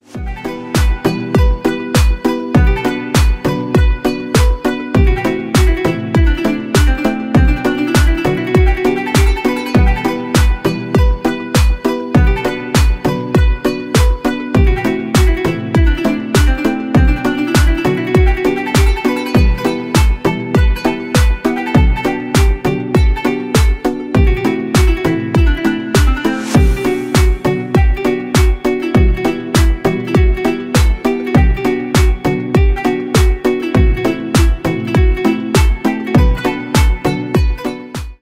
• Качество: 128, Stereo
без слов
восточные
армянские